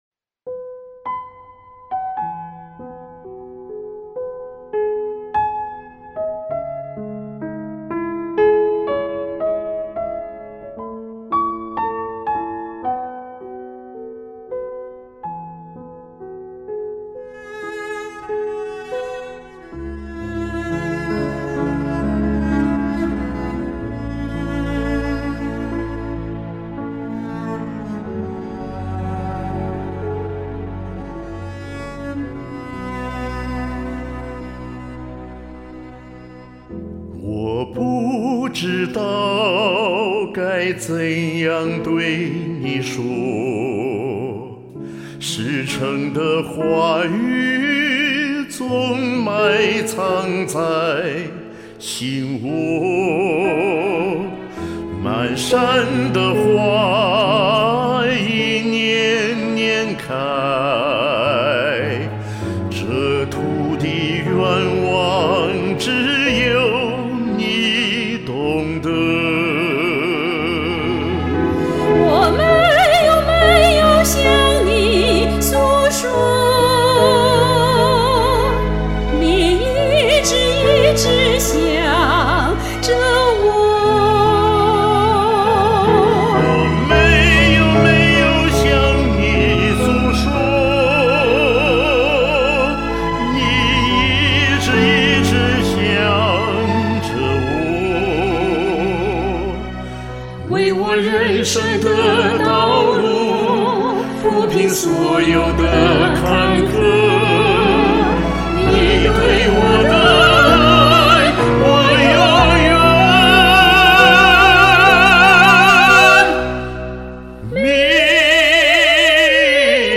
调合适，火力全开；声音相配，让美丽尽情地绽放。
细腻深情，含蓄又热烈，好动听的歌声，感人至深。
美声主旋律，高昂，有范。